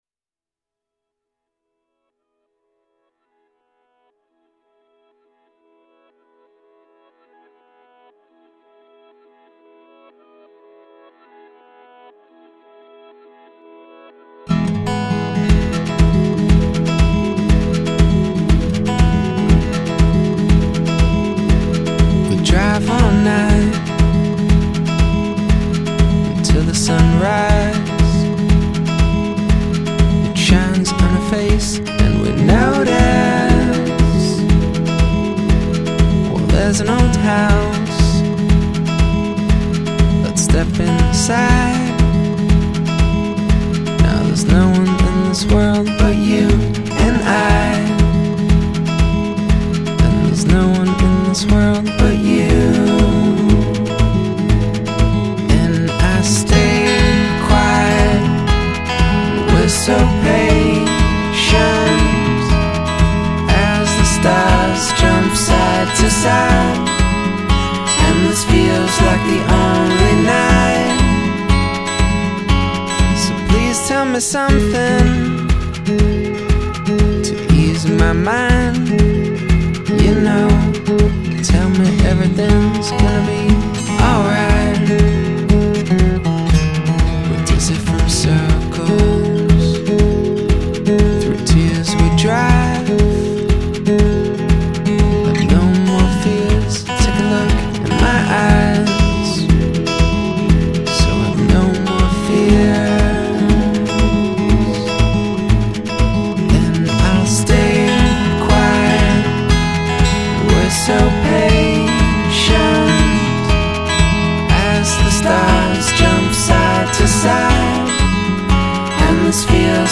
FILE UNDER: Indie / Acoustic / Other